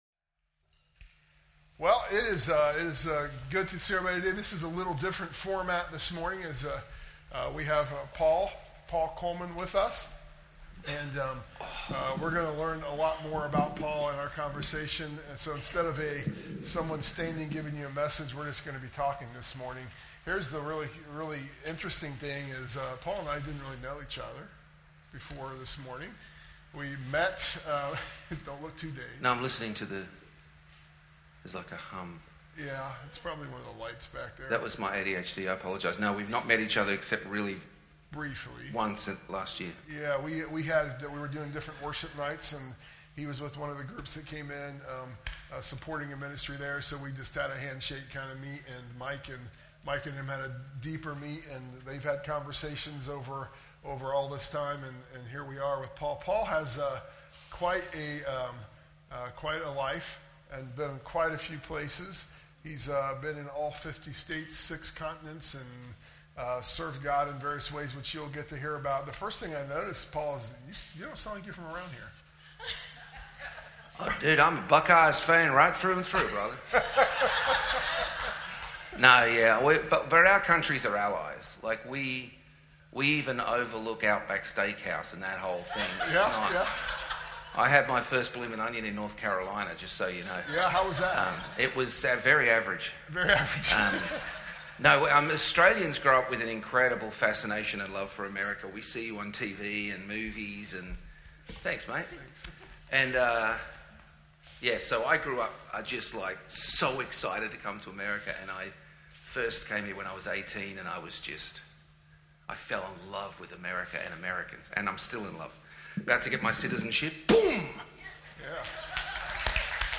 Special Guest
sermon_audio_mixdown_9_14_25.mp3